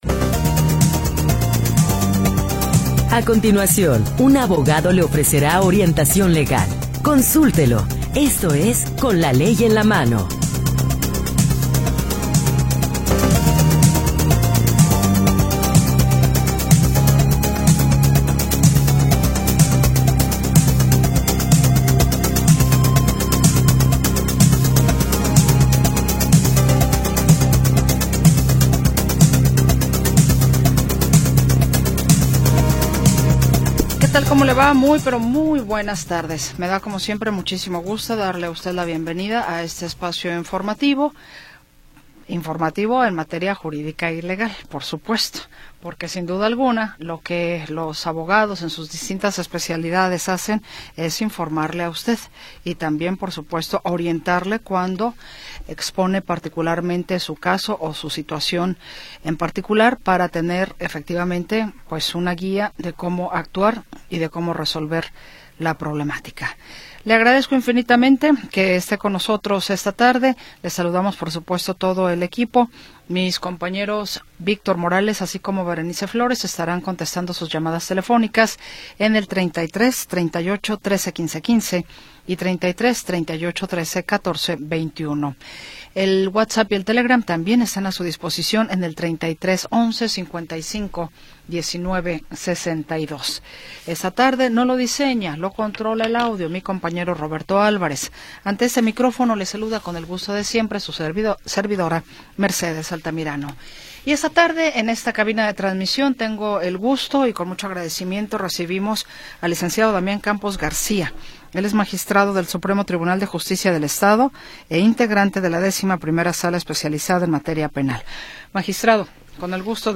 Programa transmitido el 18 de Diciembre de 2025.